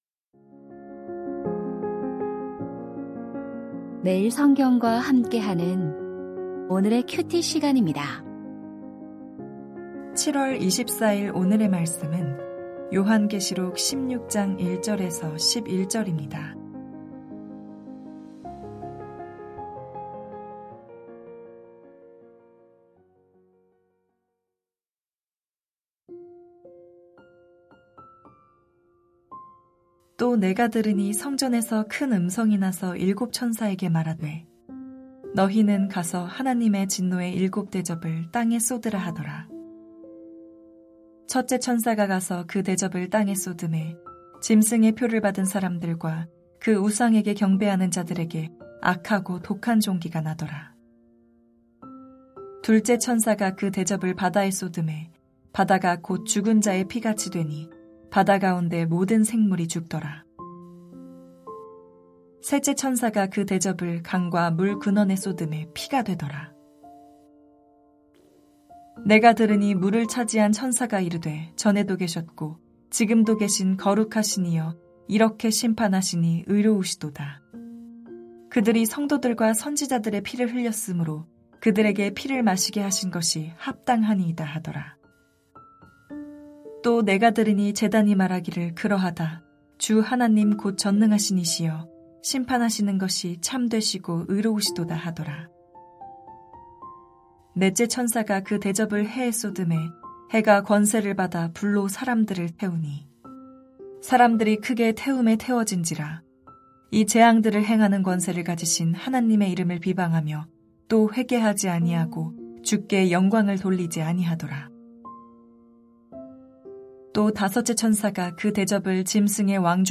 요한계시록 16:1-11 두 가지 상반된 반응 2025-07-24 (목) > 오디오 새벽설교 말씀 (QT 말씀묵상) | 뉴비전교회